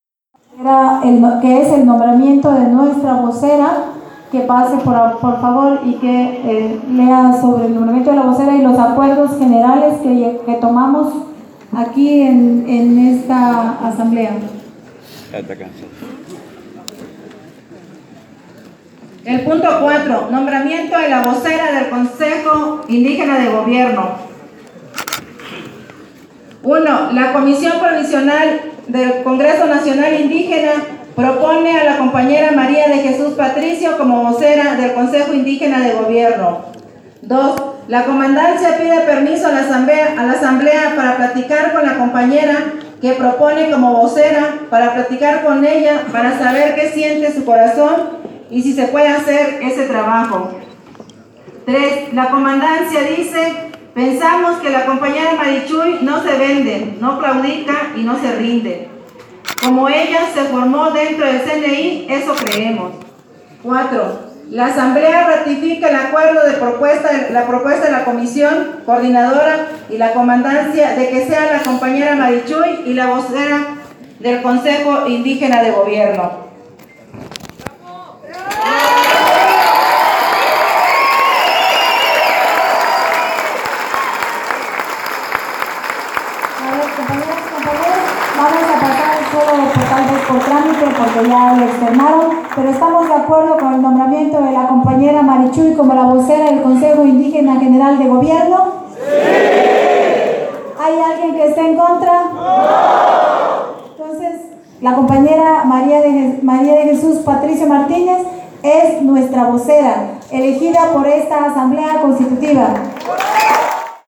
Aquí les compartimos los audios de la sesión pública de la Asamblea Constitutiva del Consejo Indígena de Gobierno para México, realizada el 28 de mayo de 2017 en el CIDECI-Unitierra en San Cristóbal de las Casas, Chiapas: